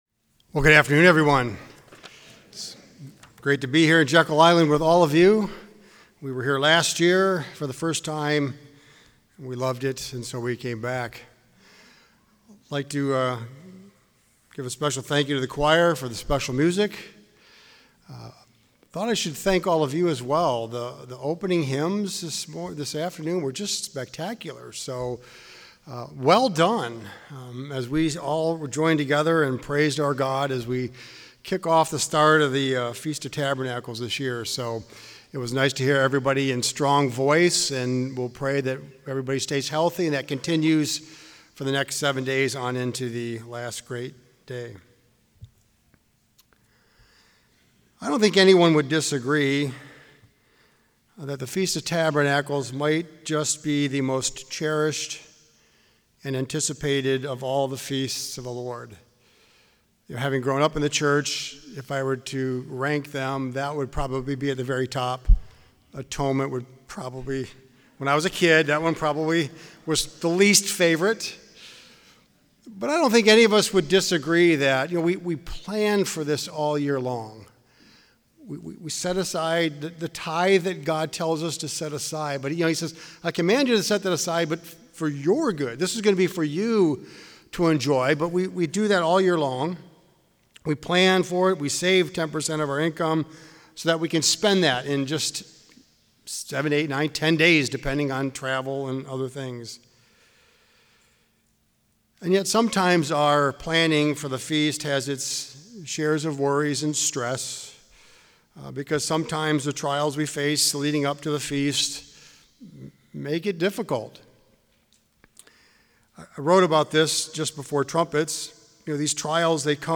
This sermon was given at the Jekyll Island, Georgia 2023 Feast site.